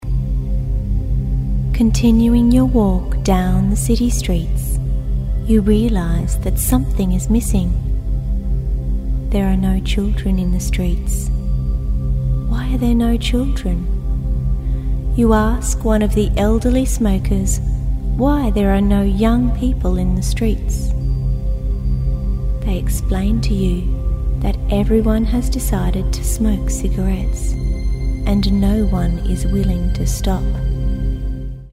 a professional voice over